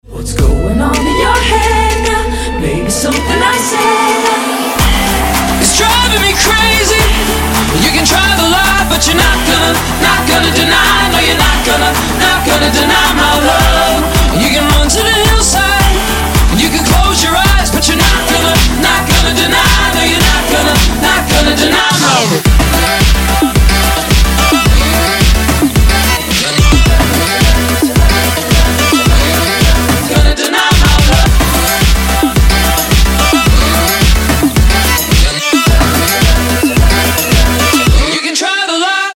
• Качество: 320, Stereo
громкие
dance
club
vocal